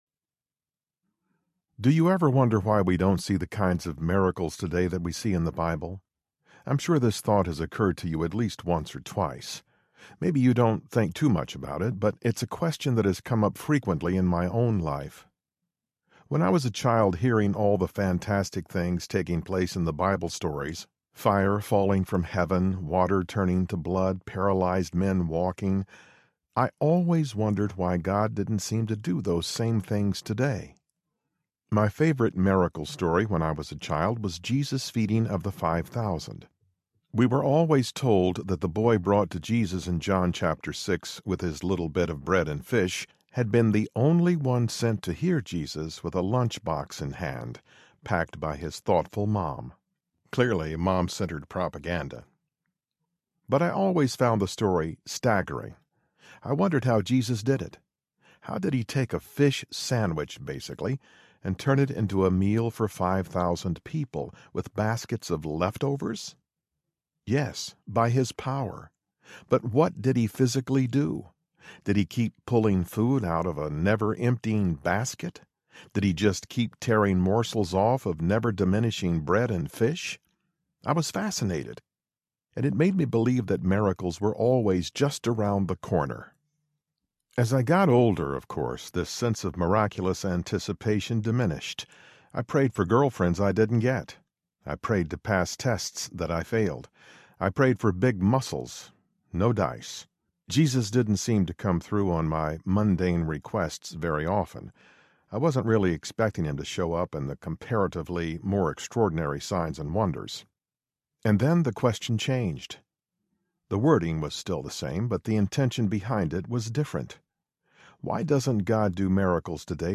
Supernatural Power for Everyday People Audiobook
6.1 Hrs. – Unabridged